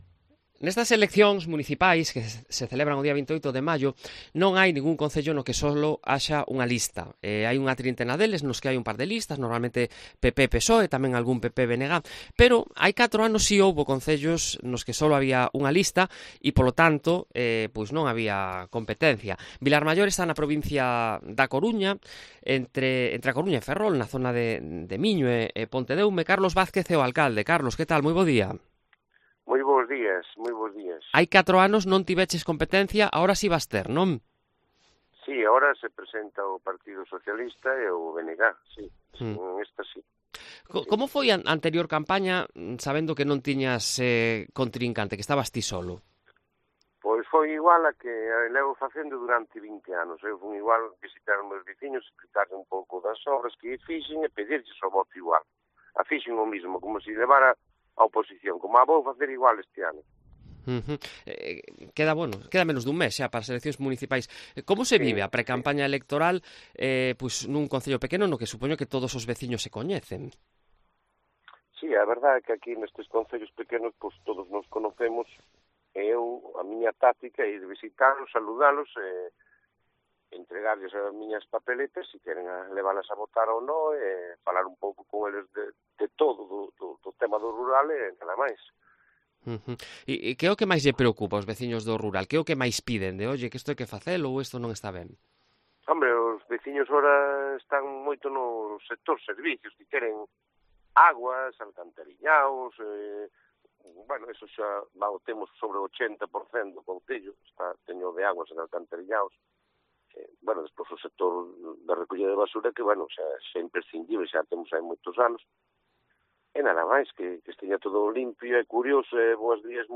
Carlos Vázquez, alcalde y candidato en Vilarmaior (A Coruña)